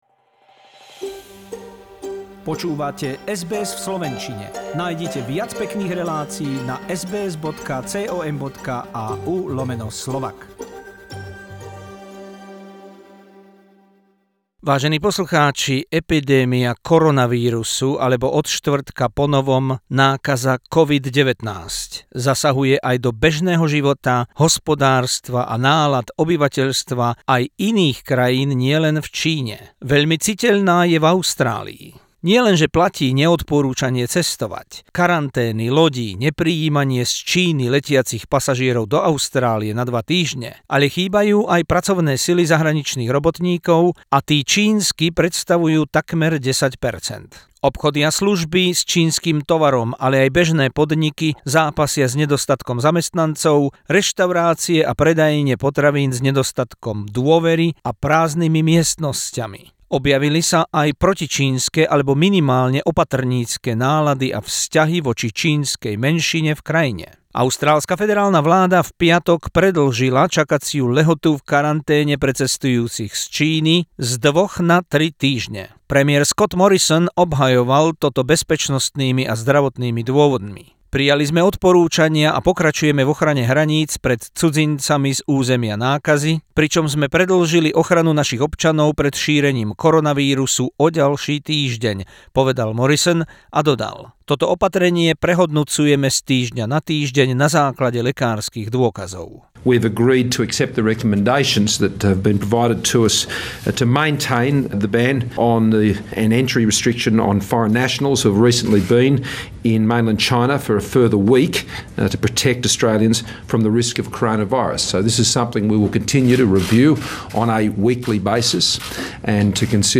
Feature about Corona-virus/COVID 19 spreading to the world, extended quarantine in Australia and more deaths in China.